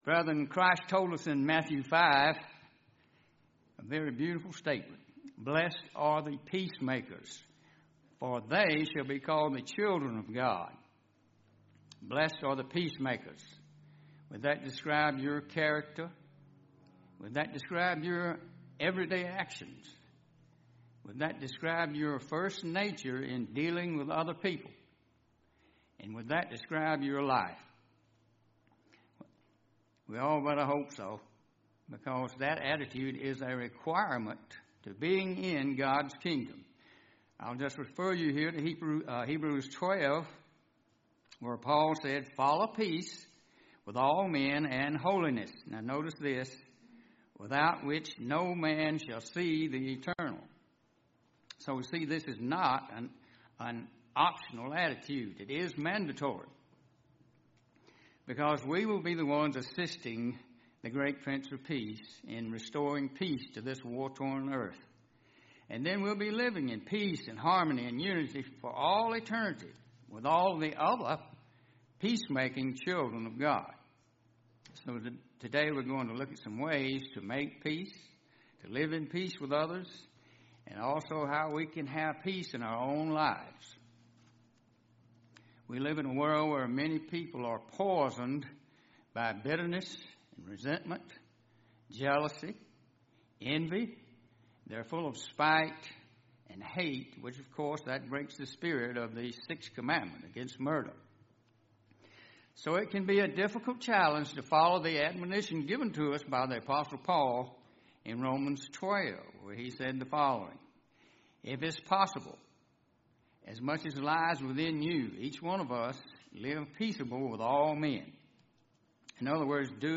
UCG Sermon Studying the bible?
Given in Columbus, GA Central Georgia